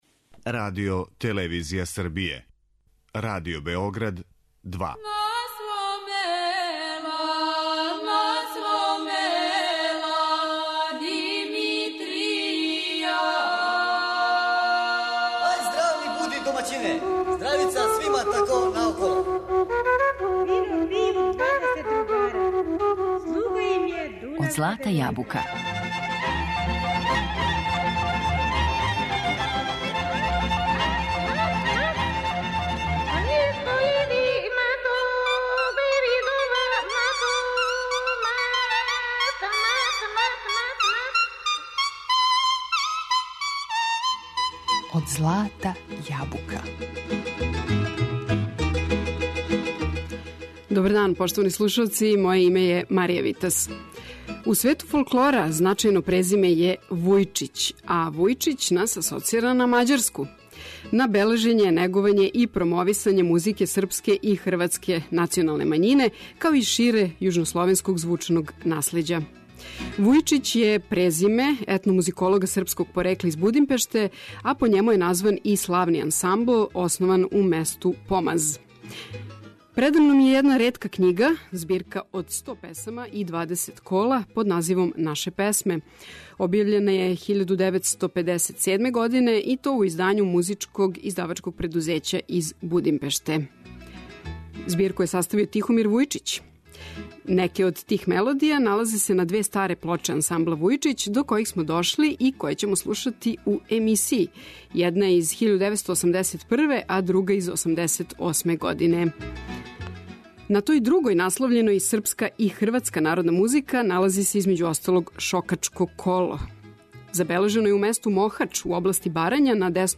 Листамо збирку 'Наше песме' из 1957. године, коју је саставио етномузиколог из Будимпеште Тихомир Вујичић и слушамо ансамбл 'Вујичић' из Сентандреје.